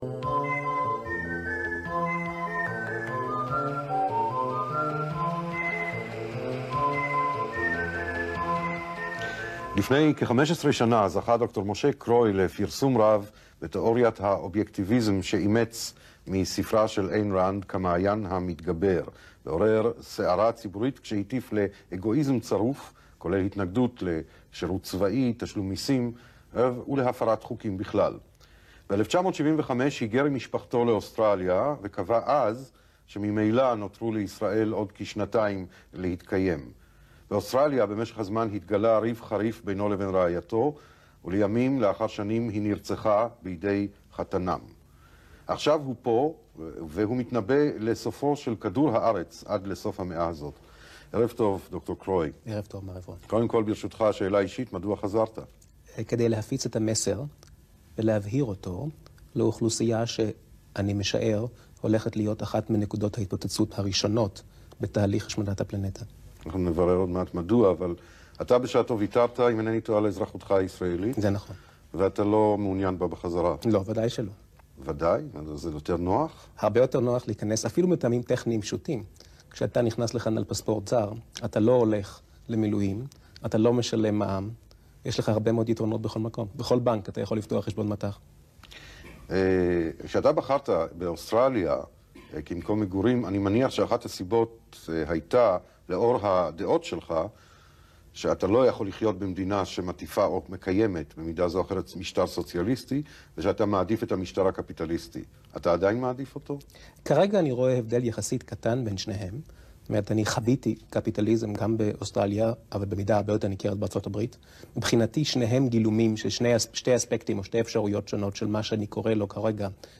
בראיון זה, מראיין אותו...